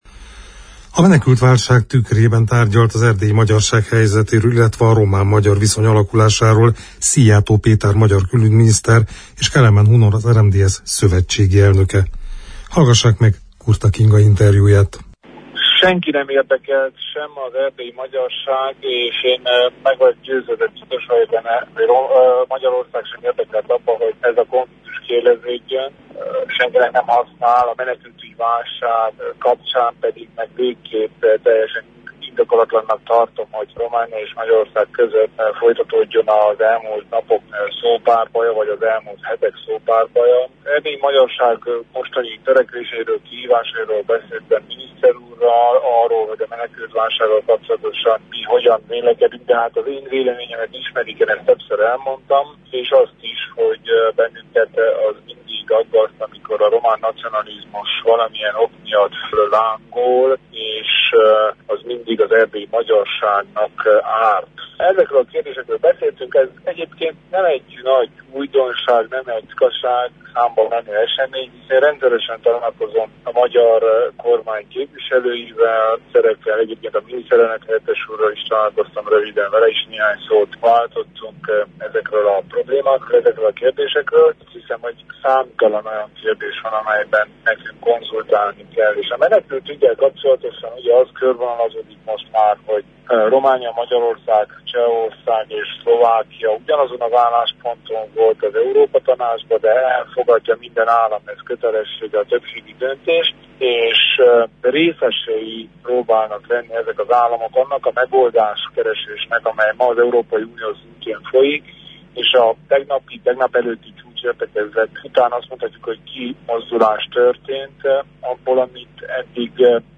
A menekültválság tükrében tárgyalt az erdélyi magyarság helyzetéről, a román-magyar viszonyról Szijjártó Péter magyar külügyminiszter és Kelemen Hunor az RMDSZ elnöke. Kelemen Hunor rádiónknak elmondta, aggasztó, amikor Romániában felerősödik a nacionalizmus és idegengyűlölet és nem tesz jót a két országnak, hogy a román-magyar viszony kiéleződjön.